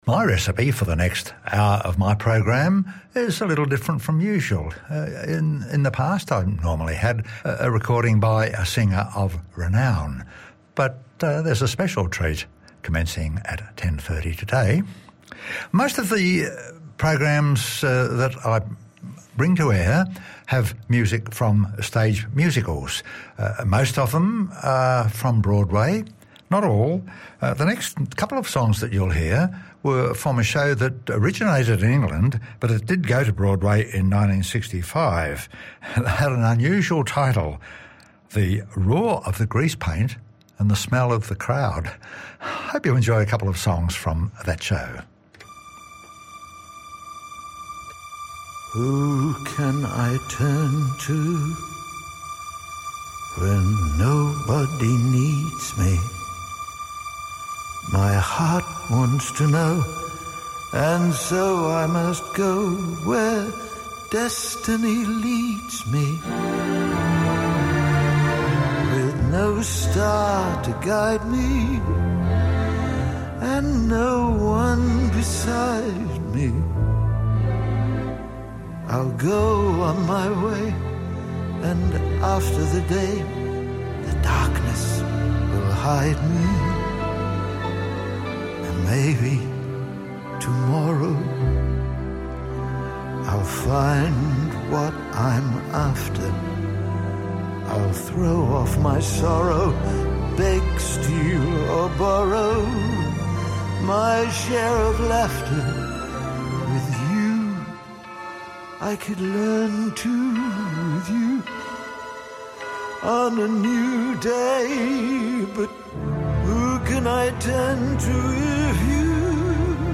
This episode is the second hour from his show on May 19.